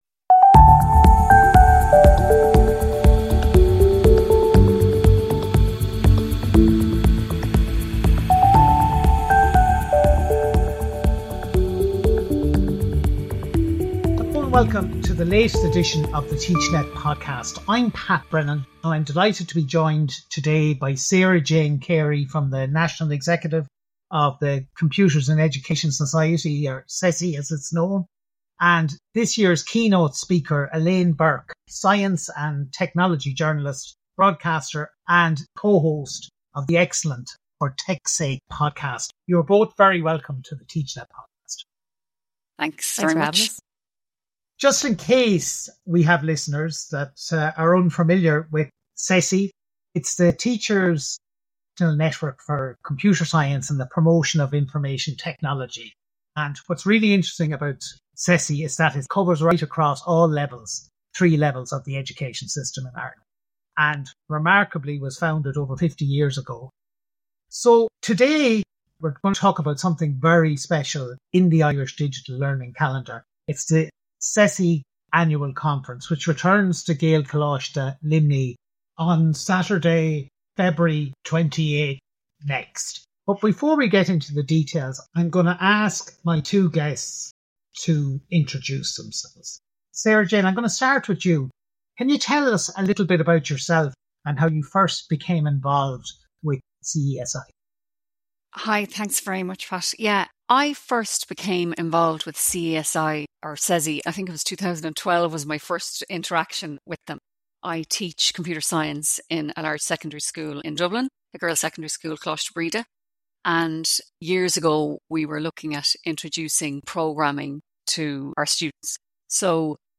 They also highlight the value of the CESI community and the practical benefits of attending the annual conference. Key points from the conversation include: TeachMeets foster informal networking among teachers.